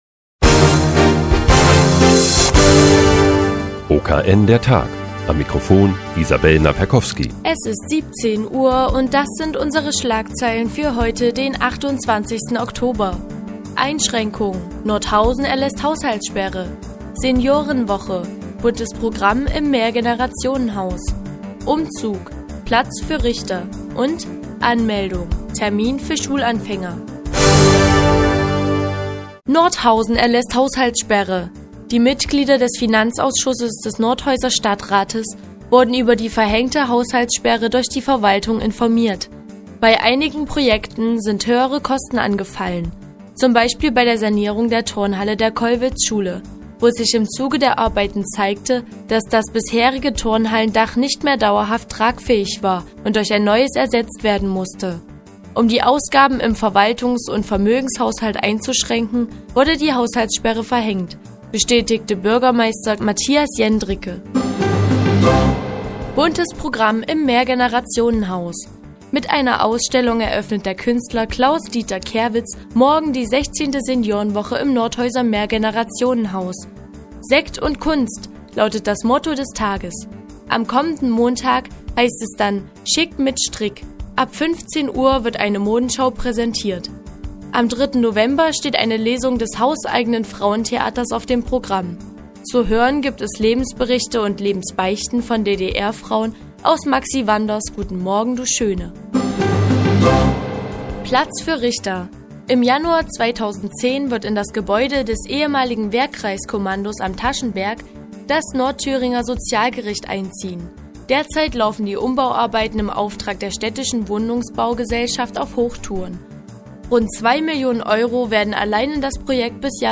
Die tägliche Nachrichtensendung des OKN ist nun auch in der nnz zu hören. Heute geht es um eine Haushaltssperre für Nordhausen und ein neues Gebäude für das Sozialgericht.